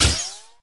minig_fire_01.ogg